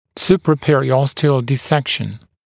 [ˌsuprəˌperɪ’ɔstɪəl dɪ’sekʃn][ˌсупрэˌпэри’остиэл ди’сэкшн]супрапериостальный разрез